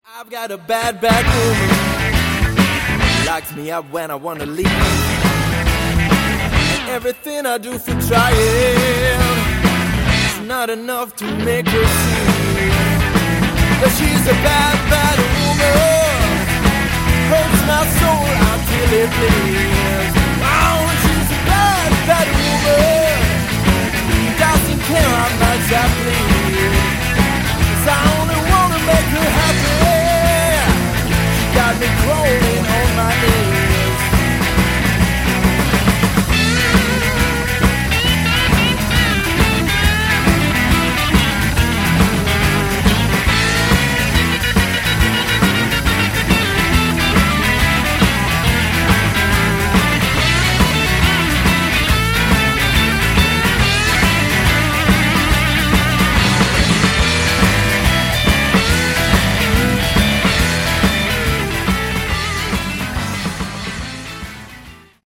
Category: Blues Rock
lead guitars
lead vocals, harmonica, rhythm guitar
drums
bass, backing vocals